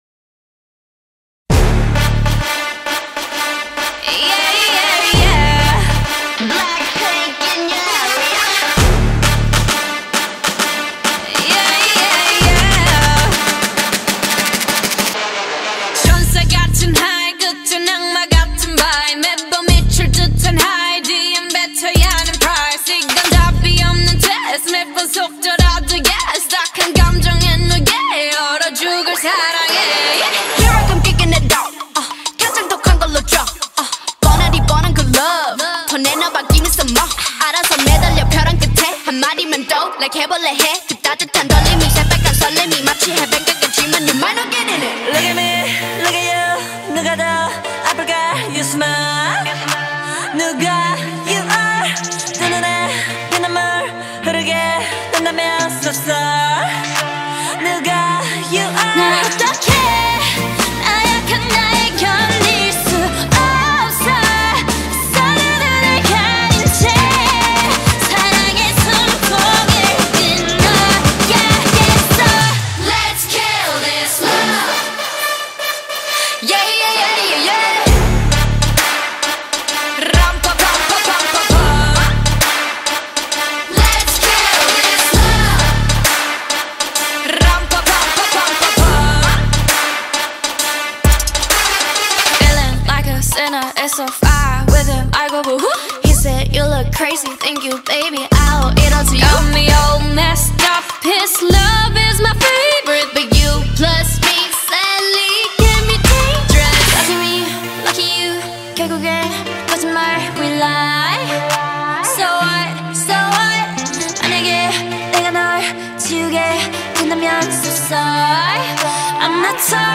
Dangdut Remix